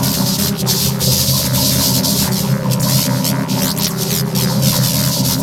alien_fan_01.ogg